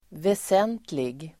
Uttal: [ves'en:tlig]